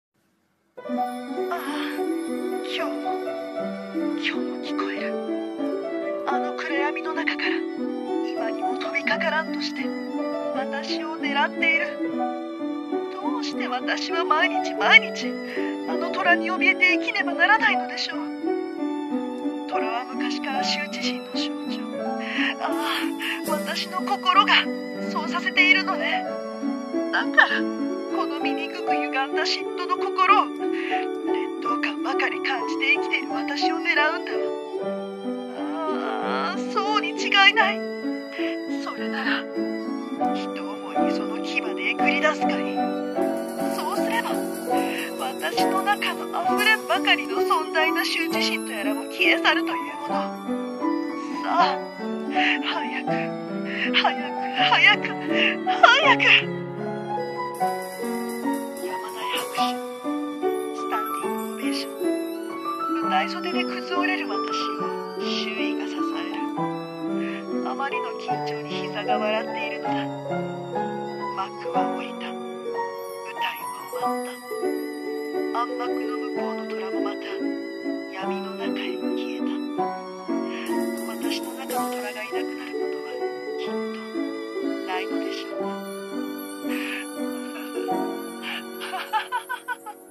【一人声劇】虎視眈々